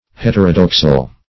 Heterodoxal \Het"er*o*dox`al\, a.